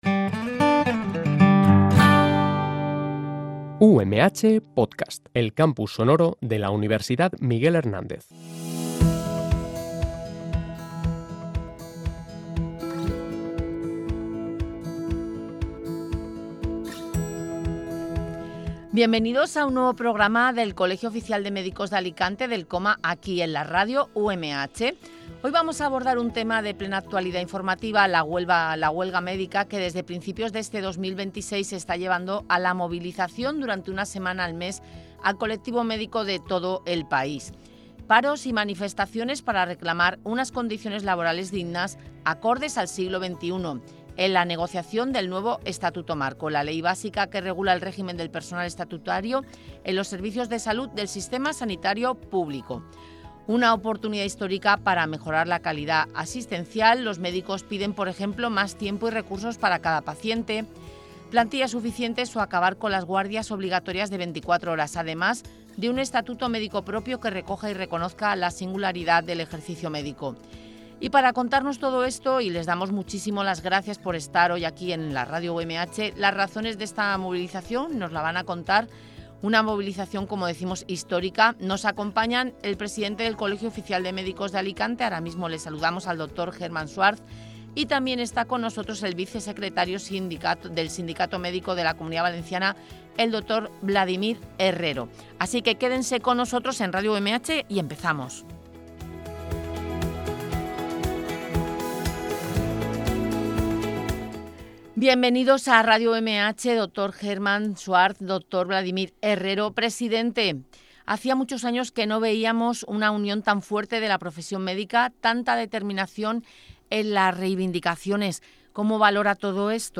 Paros y manifestaciones para reclamar unas condiciones laborales dignas, acordes al siglo XXI, en la negociación del nuevo Estatuto Marco. El espacio radiofónico puso el acento en que la sobrecarga asistencial, las maratonianas jornadas médicas y la ausencia de una regulación específica no solo afectan al colectivo médico, sino también a la seguridad del paciente y a la calidad asistencial.